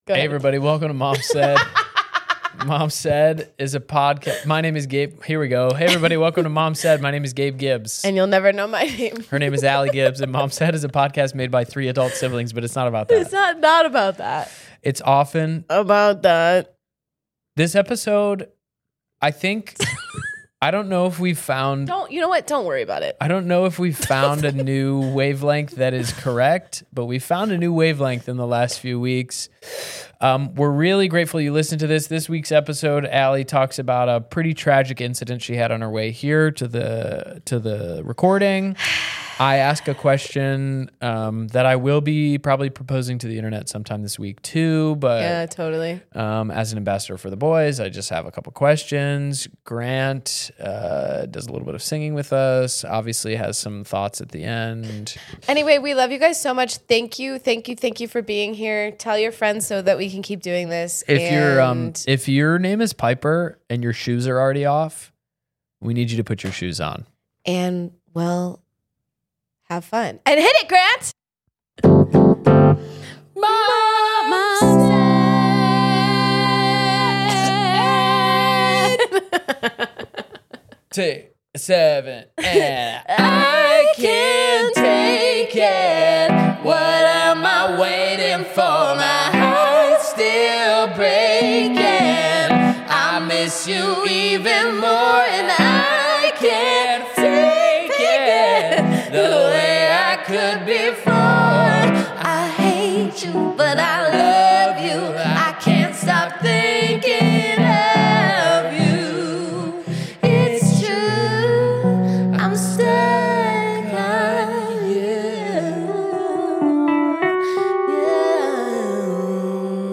Unlike the morning coffees, the siblings made it to the studio to discuss Diet Cokes for boys, angry car screams and IKYKY.